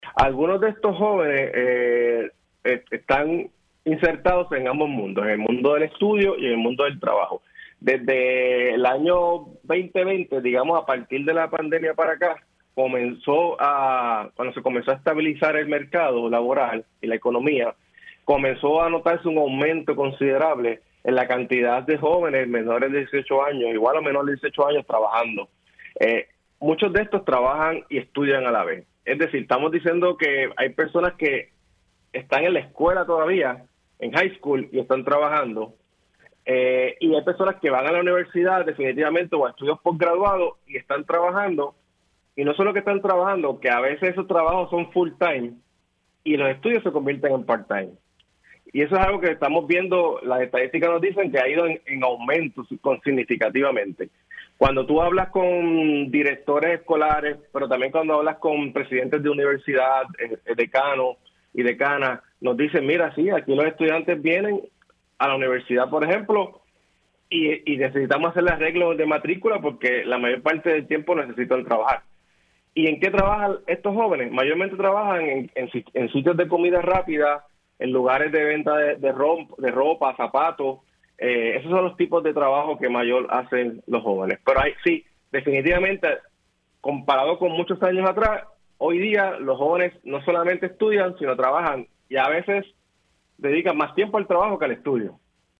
Puerto Rico es la jurisdicción más pobre de los Estados Unidos, así informó el director del Instituto de Estadísticas de Puerto Rico (IEPR), Orville Disidier en Pega’os en la Mañana.